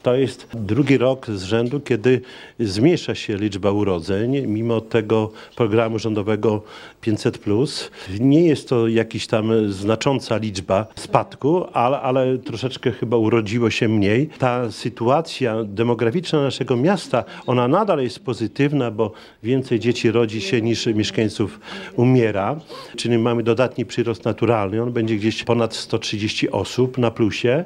Jak dodał prezydent, sytuacja demograficzna w Suwałkach jest dobra, jednak w 2018 urodzeń było nieco mniej niż przed rokiem.